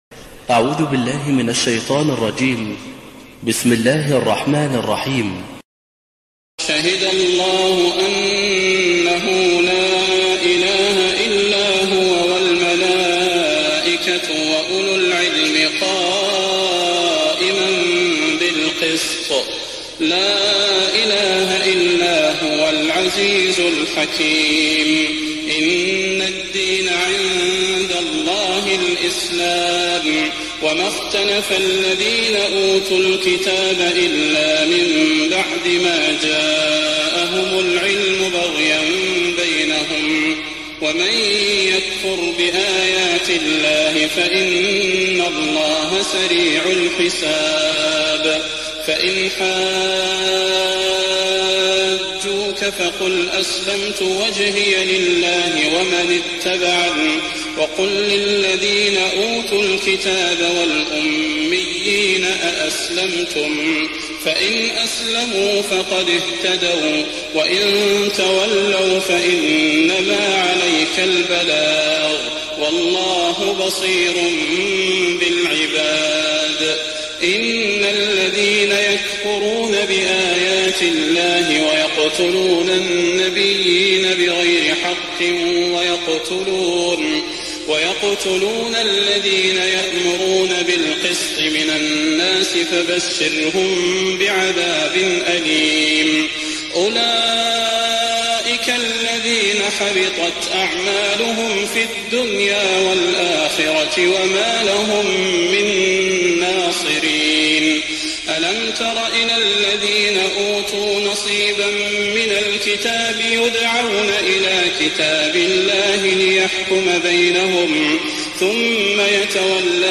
تهجد ليلة 23 رمضان 1419هـ من سورة آل عمران (18-129) Tahajjud 23rd night Ramadan 1419H from Surah Aal-i-Imraan > تراويح الحرم النبوي عام 1419 🕌 > التراويح - تلاوات الحرمين